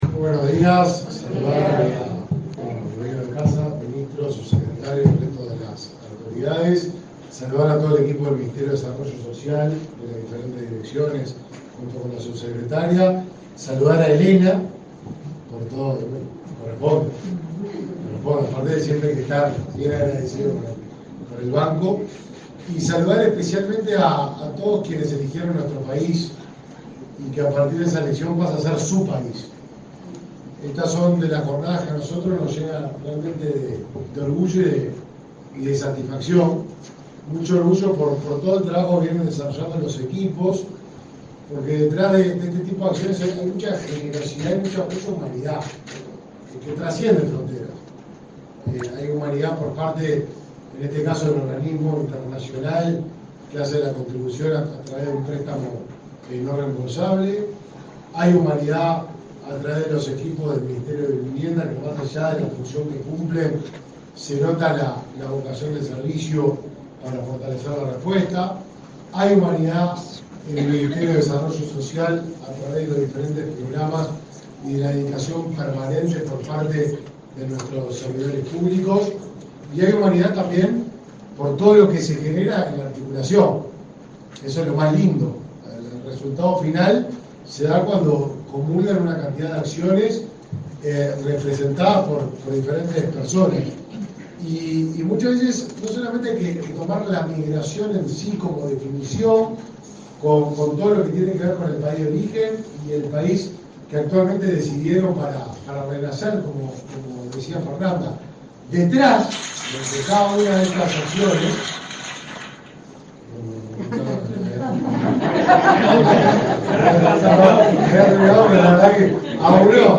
Acto por la firma de convenio entre el MVOT y el Mides
Acto por la firma de convenio entre el MVOT y el Mides 23/10/2023 Compartir Facebook X Copiar enlace WhatsApp LinkedIn El Ministerio de Vivienda y Ordenamiento Territorial (MVOT) firmó un convenio con el Ministerio de Desarrollo Social (Mides), este 23 de octubre, mediante el cual se concretaron subsidios de alquiler para familias migrantes. Participaron en el evento los ministros Raúl Lozano y Martín Lema.